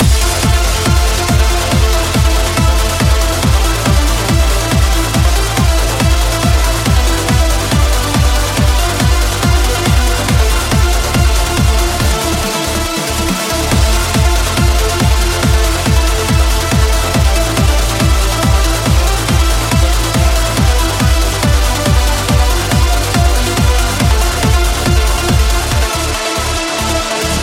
uplifting trance
Genere: trance, uplifting trance